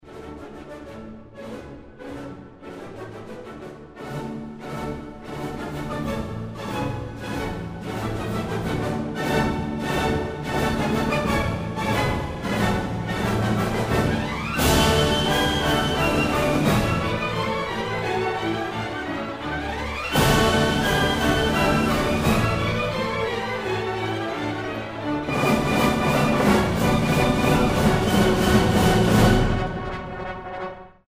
RACHMANINOFF Piano Concerto No. 2 Keyboard pyrotechnics and expressive melodies.